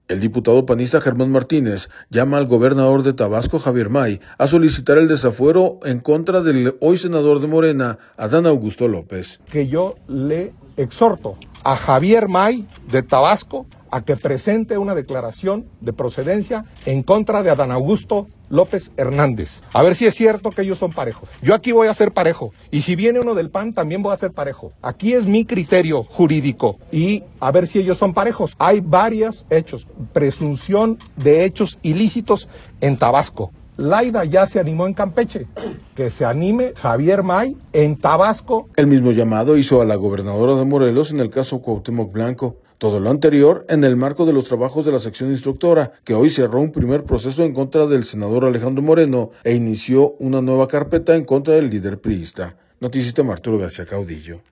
audio El diputado panista Germán Martínez llama al gobernador de Tabasco, Javier May, a solicitar el desafuero en contra del hoy senador de Morena, Adán Augusto López.